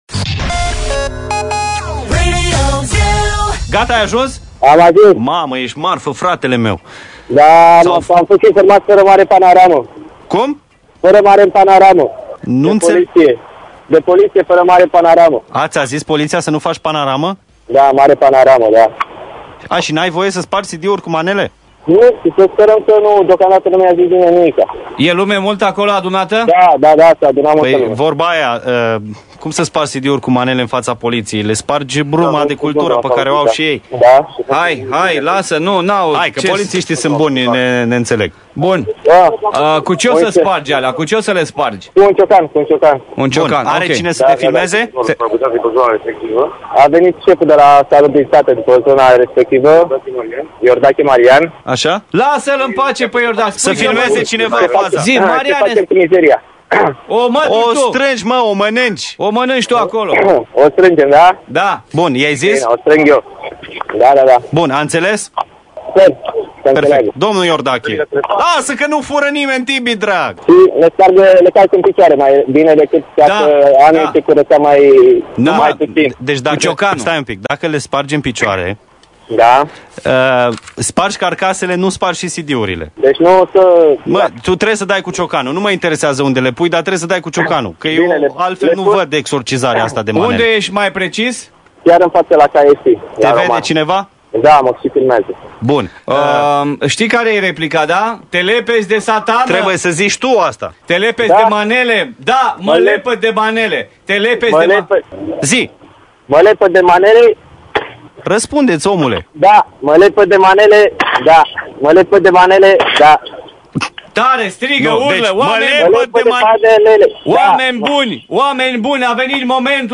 Cadru: KFC- Piata Romana. Aroganta: sa cumpere 10 cd-uri cu manele si sa le faca tandari.